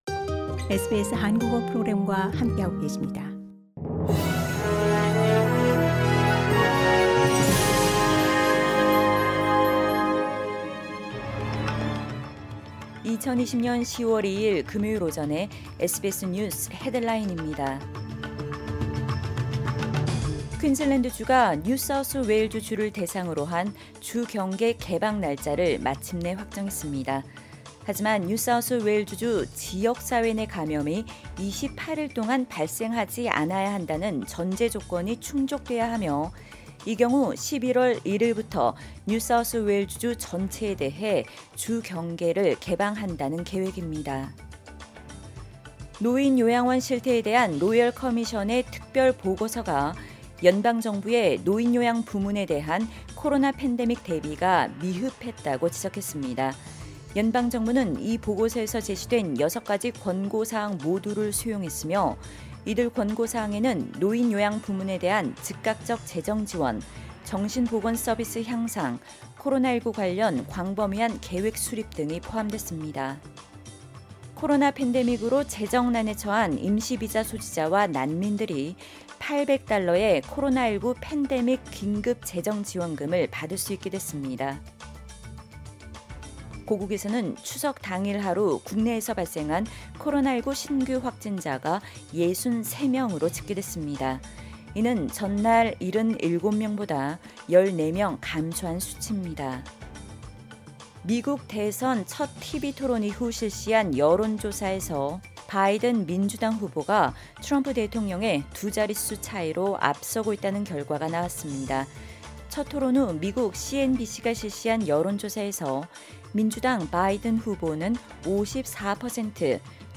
2020년 10월 2일 금요일 오전의 SBS 뉴스 헤드라인입니다.